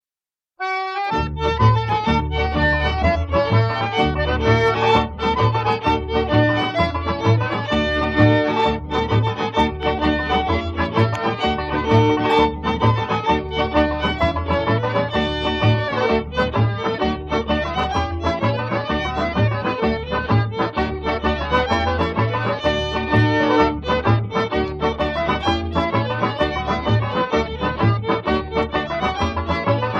Blackberry Quadrille / Soldier's Joy Patter (two instrumentals)